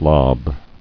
[lob]